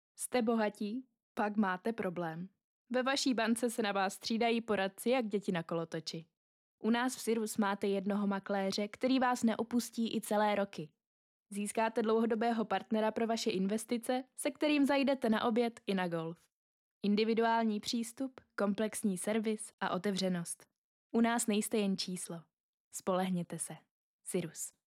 Profesionální ženský hlas - voiceover/dabing (do 400 znaků)